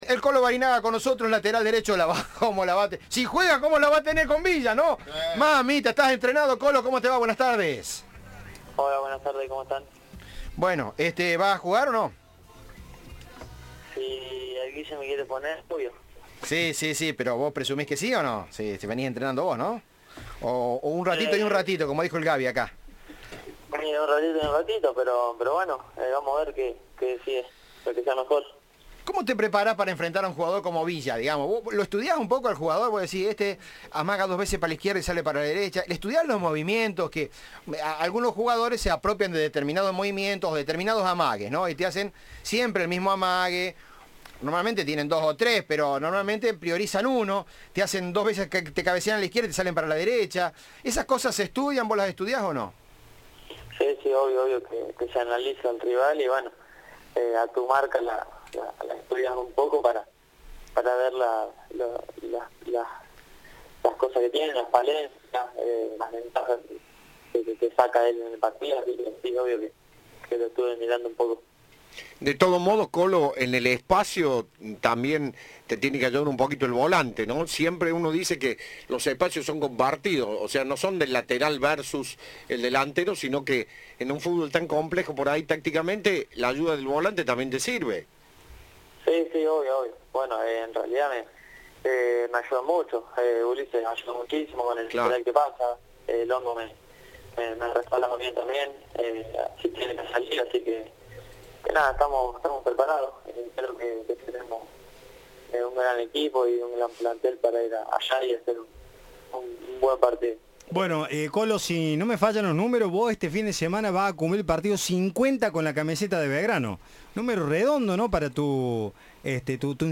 Entrevista de Tiempo de Juego